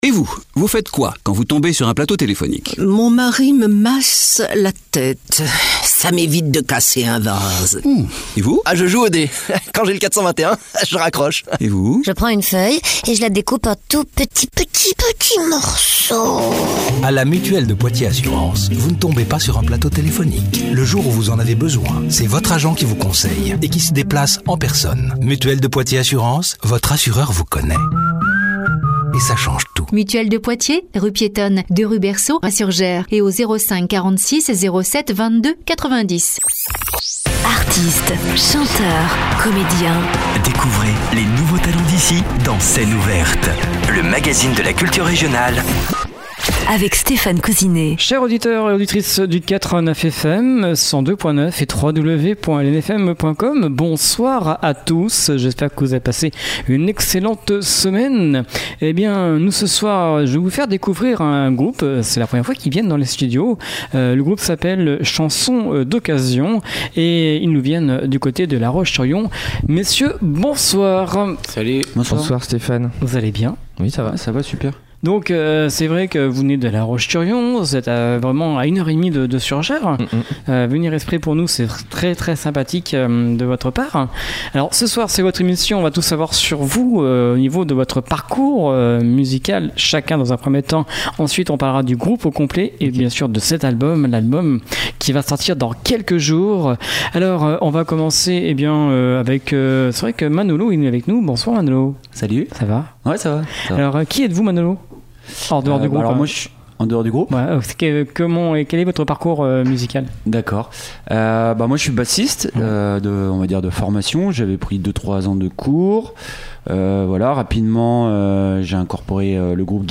relookées façon swing manouche.
Guitare, chant
Contrebasse